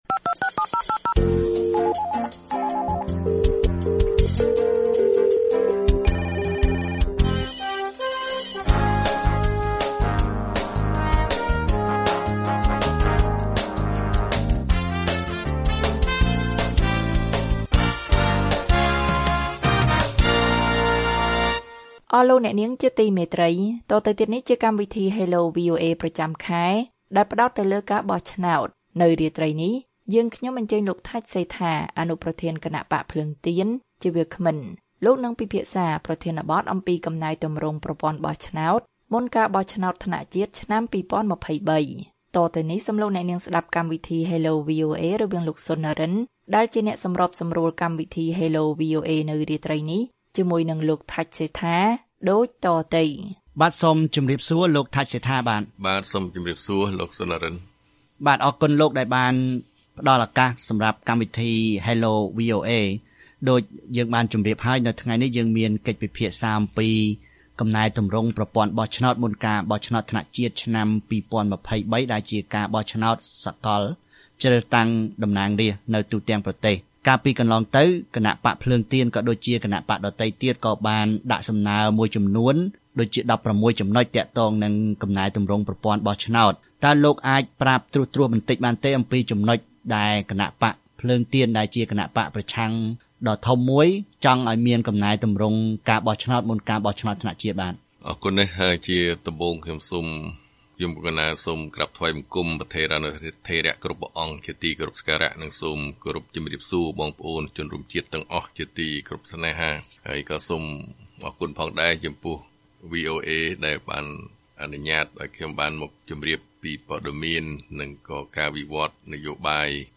លោក ថាច់ សេដ្ឋា អនុប្រធានគណបក្សភ្លើងទៀនដែលជាគណបក្សប្រឆាំងដ៏ធំមួយ បានថ្លែងនៅក្នុងកម្មវិធី Hello VOA ថា ប្រព័ន្ធបោះឆ្នោតនាពេលបច្ចុប្បន្ននេះ មិនទាន់មានភាពល្អប្រសើរនៅឡើយទេ ហើយលោកកំពុងជំរុញឱ្យមានការកែប្រែ ដើម្បីធានាថា ការបោះឆ្នោតនាពេលខាងមុខនេះ អាចទទួលយកបាន។